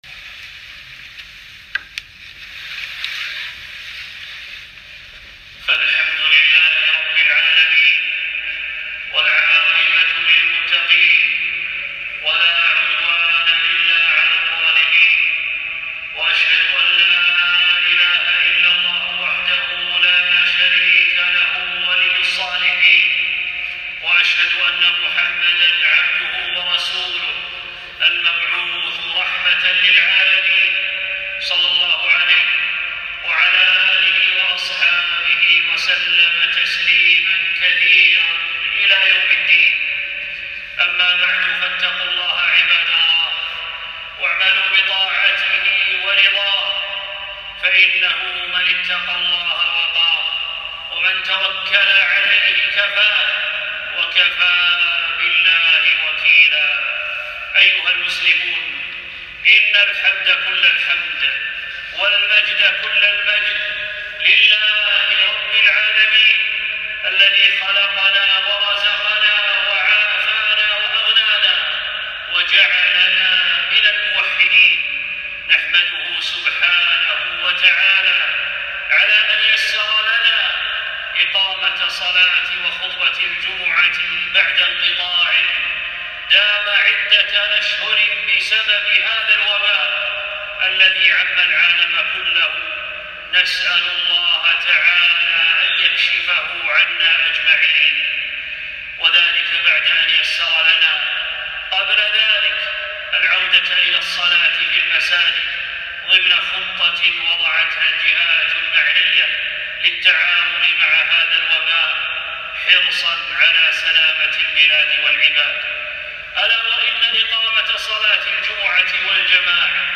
فبذلك فليفرحوا - أول خطبة بعد الانقطاع بسبب كورونا